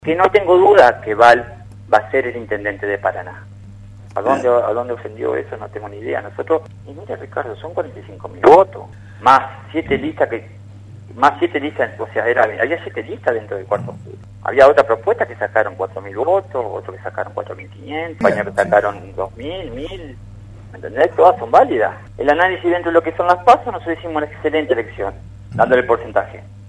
DECLARACIONES A RADIO RD 99.1